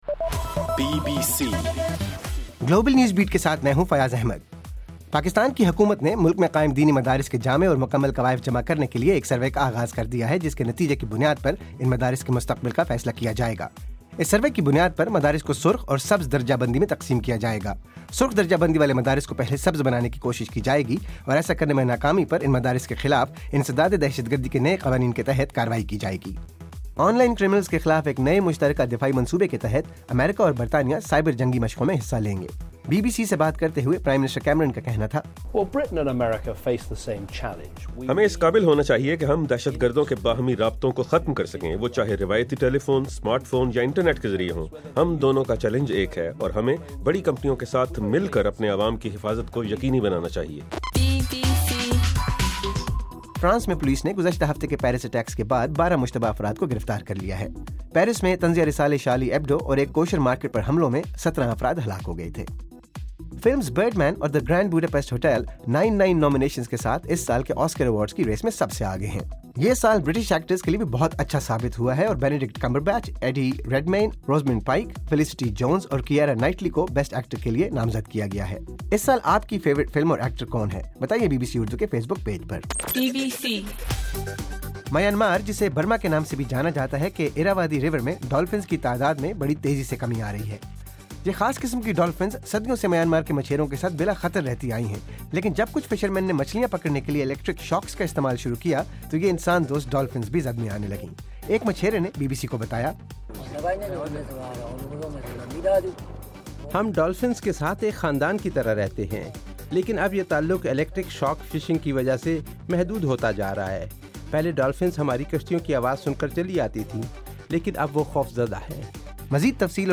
جنوری 16: رات 12 بجے کا گلوبل نیوز بیٹ بُلیٹن